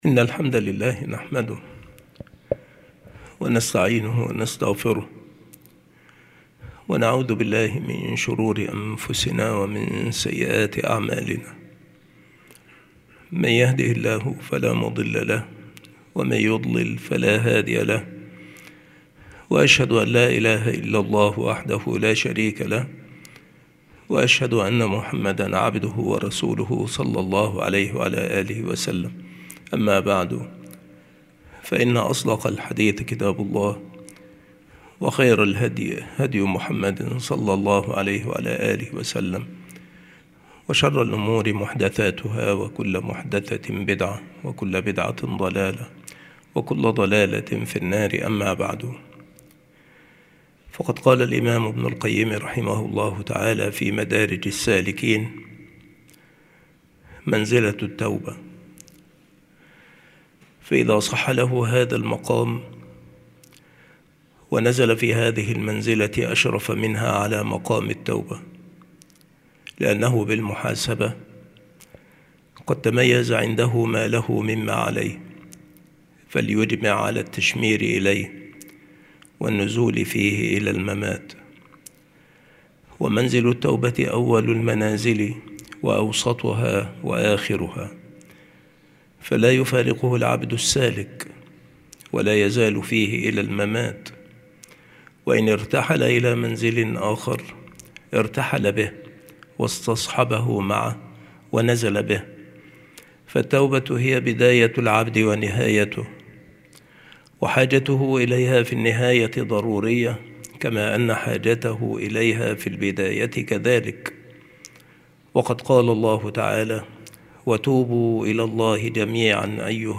مكان إلقاء هذه المحاضرة المكتبة - سبك الأحد - أشمون - محافظة المنوفية - مصر عناصر المحاضرة : منزلة التوبة. انتظام سورة الفاتحة للتوبة أحسن انتظام.